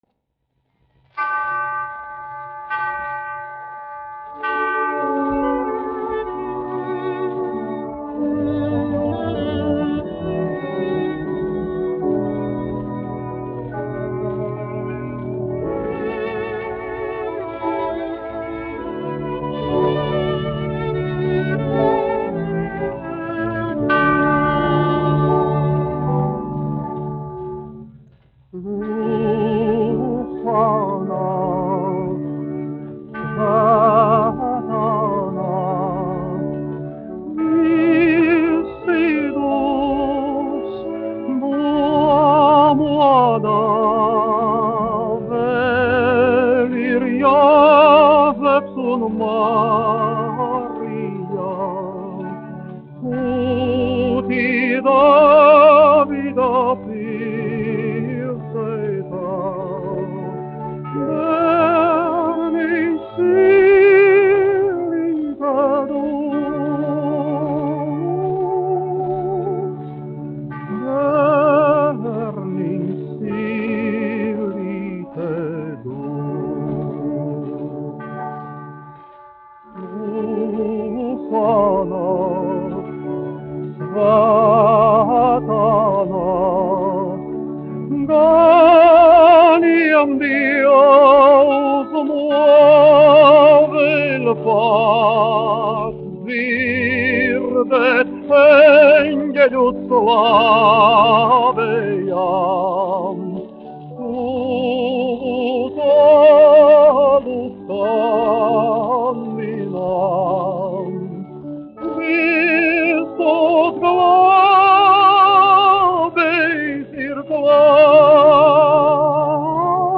1 skpl. : analogs, 78 apgr/min, mono ; 25 cm
Korāļi
Ziemassvētku mūzika